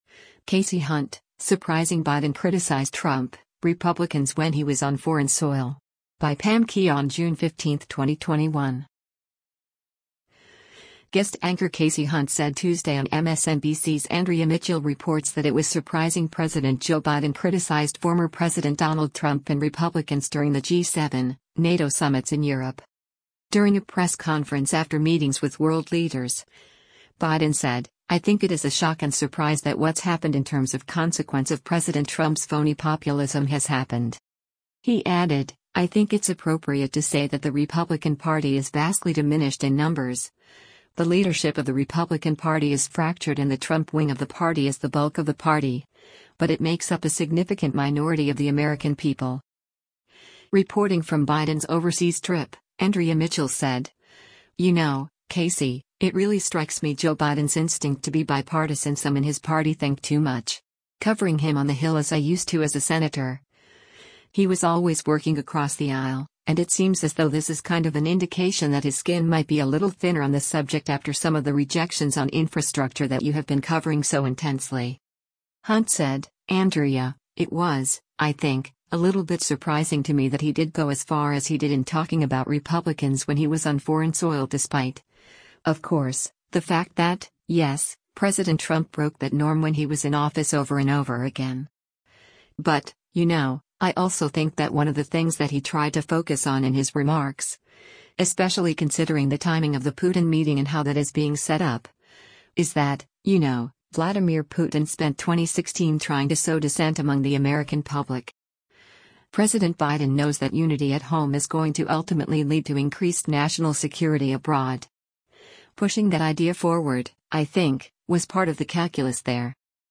Guest anchor Kasie Hunt said Tuesday on MSNBC’s “Andrea Mitchell Reports” that it was “surprising” President Joe Biden criticized former President Donald Trump and Republicans during the G-7, NATO summits in Europe.